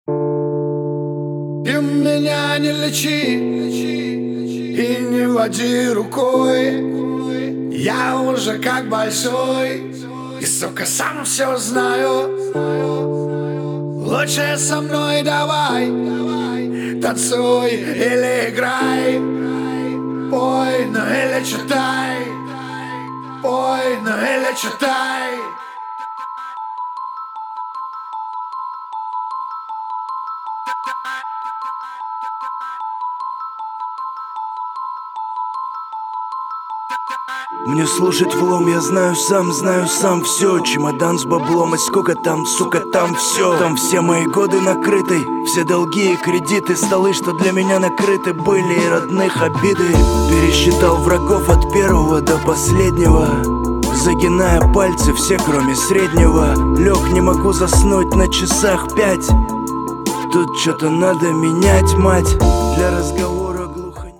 • Качество: 320, Stereo
сиплые